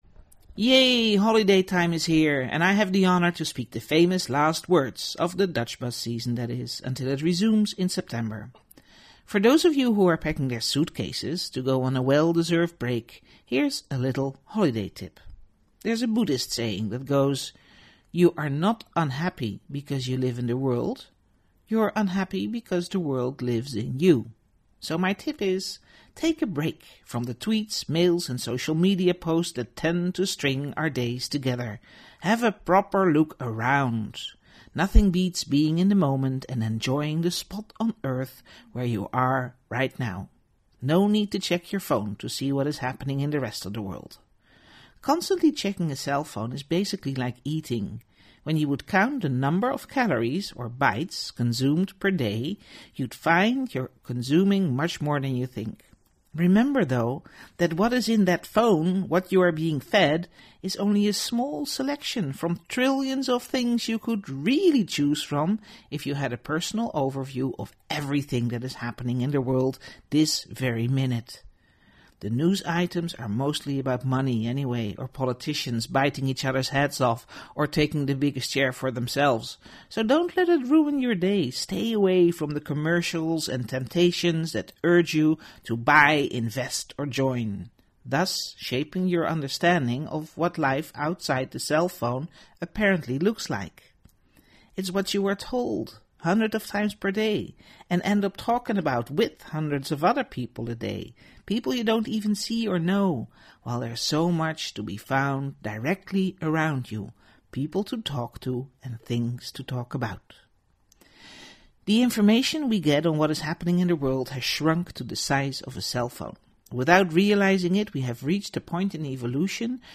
3 minute (abridged) recording of the end-of-the-season Dutchbuzz blog of June 25th 2019, ‘The treadmill of tweets”